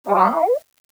fox2.wav